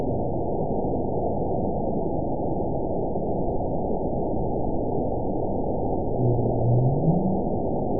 event 921761 date 12/18/24 time 22:18:58 GMT (4 months, 2 weeks ago) score 9.46 location TSS-AB02 detected by nrw target species NRW annotations +NRW Spectrogram: Frequency (kHz) vs. Time (s) audio not available .wav